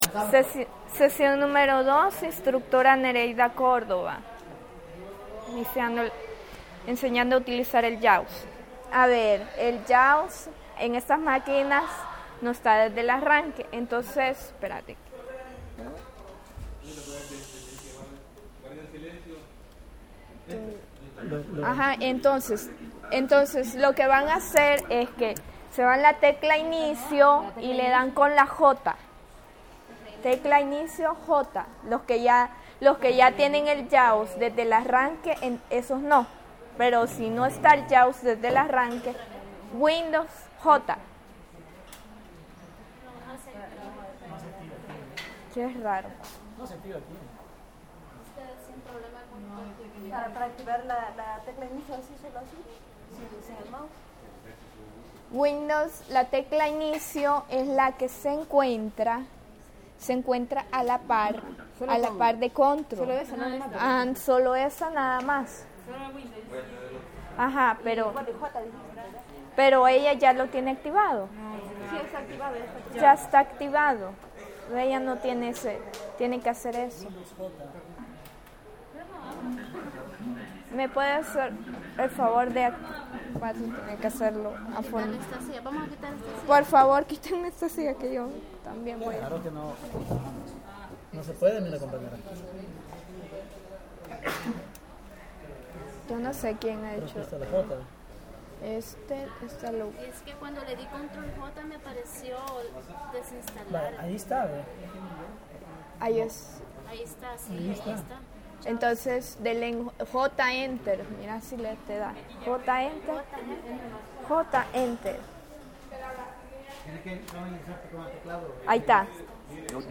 Clase práctica sobre el uso del programa para usuarios no videntes JAWS.